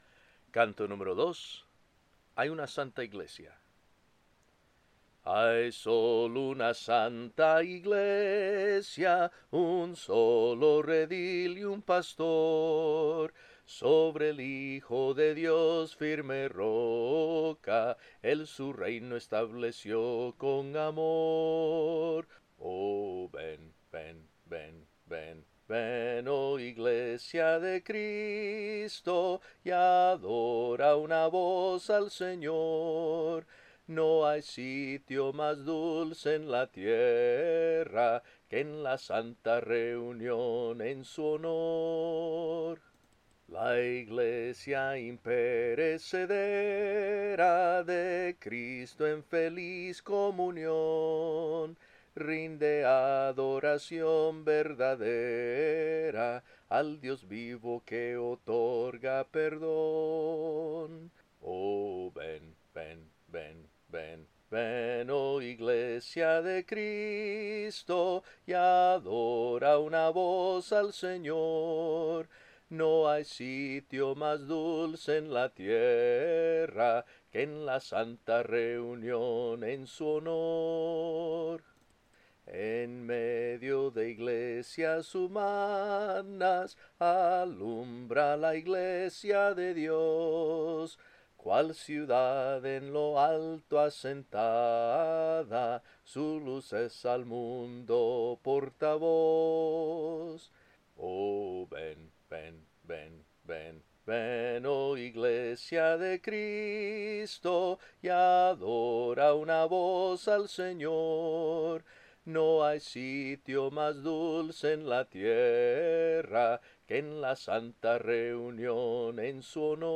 Se ha optado por interpretar únicamente la melodía, prescindiendo de las voces de armonía (alto, tenor y bajo) con el propósito de facilitar el proceso de aprendizaje. Al centrarse exclusivamente en el soprano (la melodía), el oyente puede captar con mayor claridad las notas y matices sonoros.